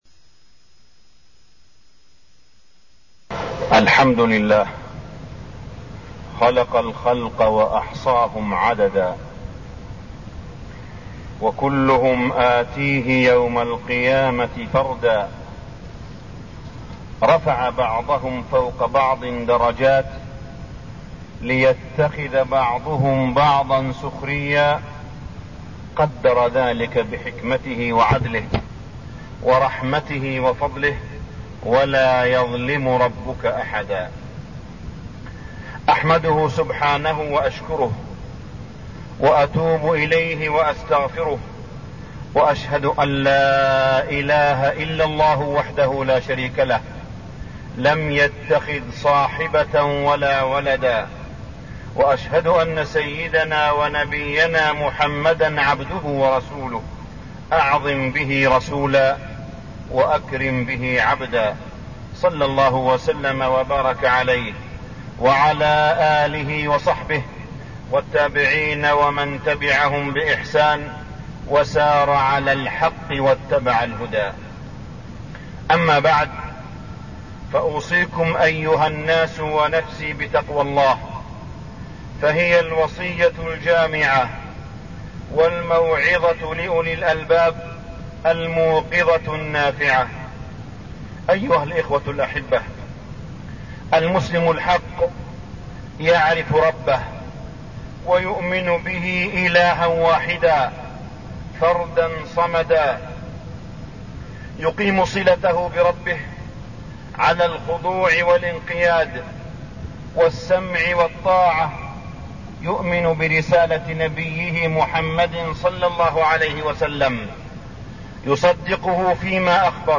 تاريخ النشر ٧ شعبان ١٤١٣ هـ المكان: المسجد الحرام الشيخ: معالي الشيخ أ.د. صالح بن عبدالله بن حميد معالي الشيخ أ.د. صالح بن عبدالله بن حميد مبادئ الإسلام في حياتنا